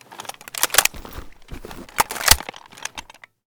groza_reload.ogg